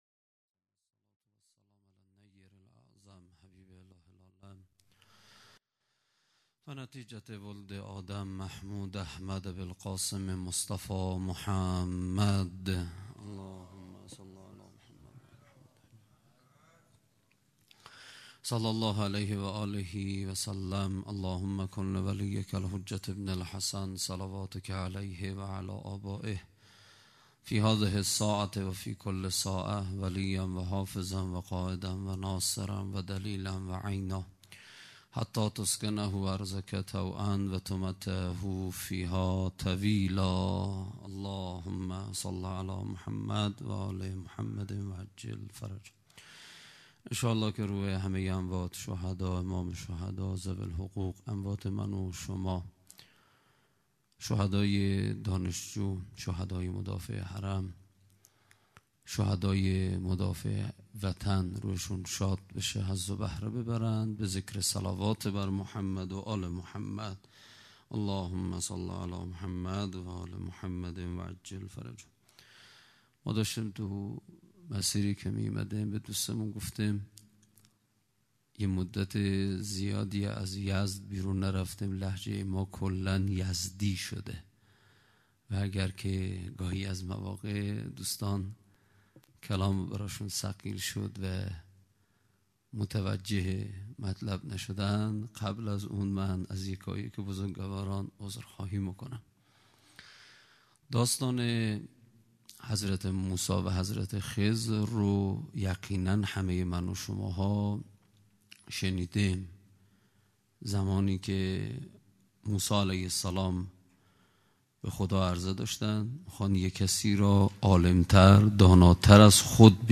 سخنرانی
ولادت امام باقر (ع) | ۱۴ اسفند ۹۷